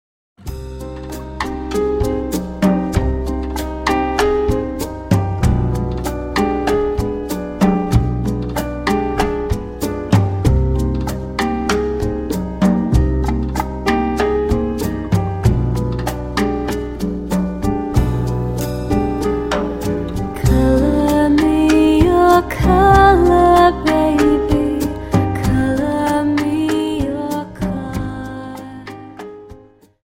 Dance: Rumba